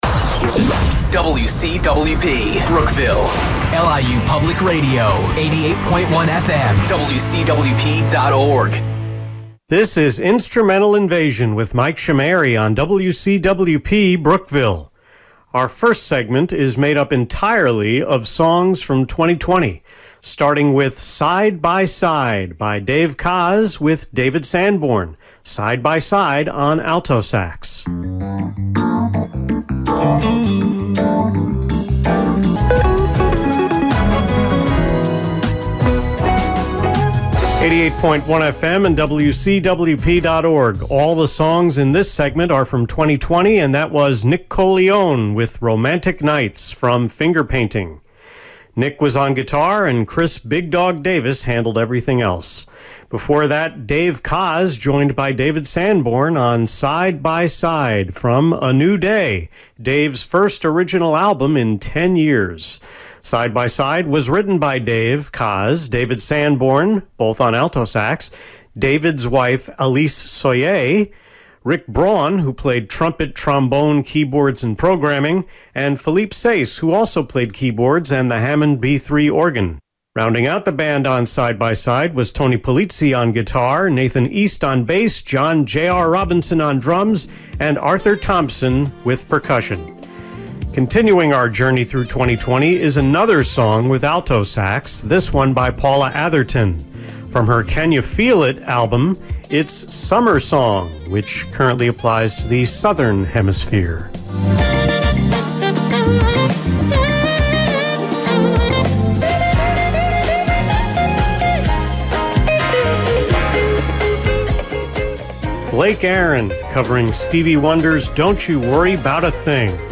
The December 9, 2020, Instrumental Invasion on WCWP was recorded one hour per day on November 11 and 12.
Jazz
Nonetheless, the end of the show was cut off again.
Last week, I dubbed tenor sax as the “MVP” of the show since it was in so many songs. This week, it was the Hammond B-3 organ .